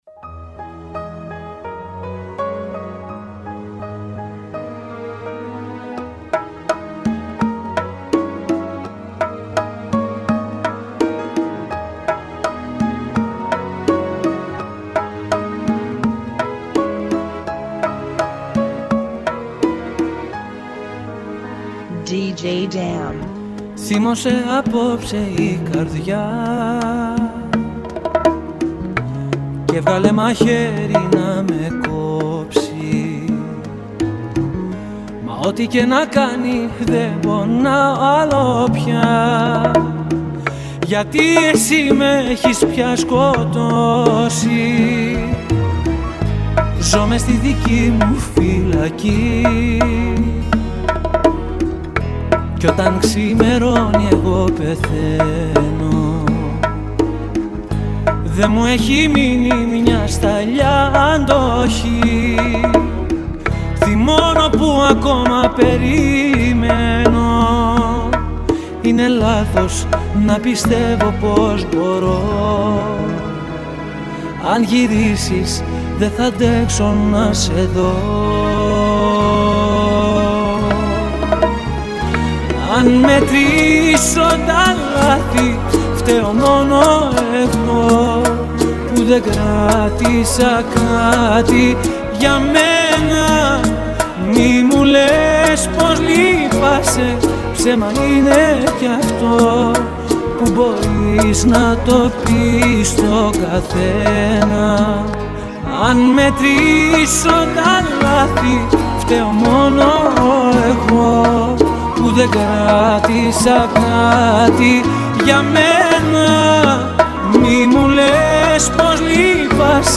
83 BPM
Genre: Bachata Remix